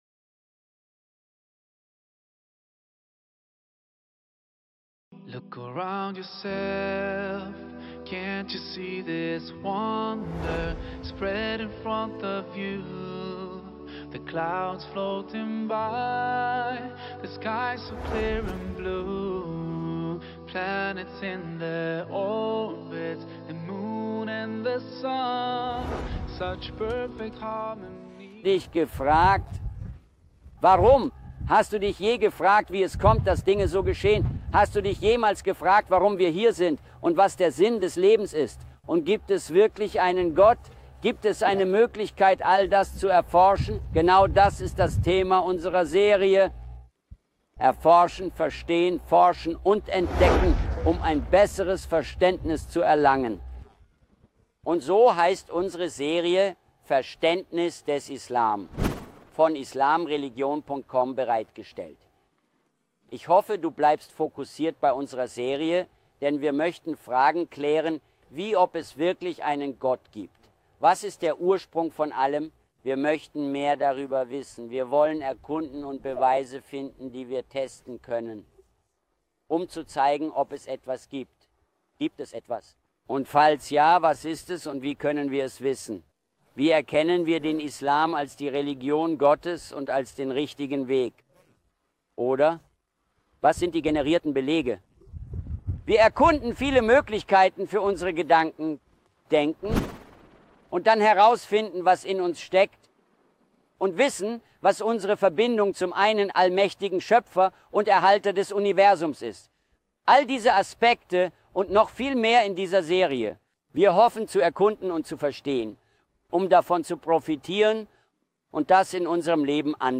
gedreht vor der Kulisse beeindruckender Landschaften und historischer Stätten Jordaniens.